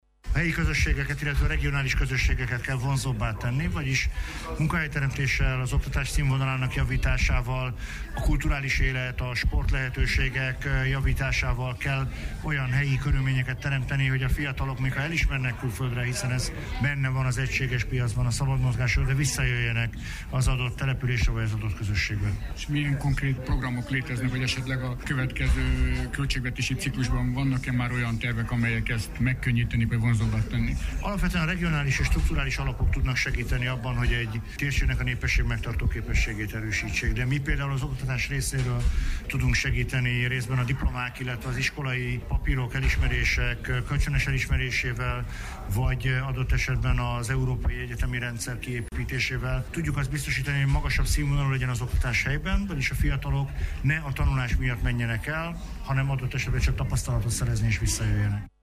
Az Európai Unió a tagállamokban szerzett oklevelek elismerésében, az oktatás fejlesztésében tudja segíteni az otthon-maradást, mondta Navracsics Tibor, oktatásért, kultúráért, ifjúságpolitikáért és sportért felelő uniós biztos.